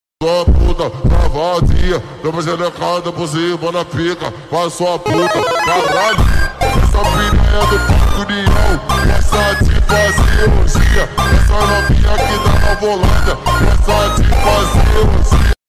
plantas vs zombies pvz pvz2 sound effects free download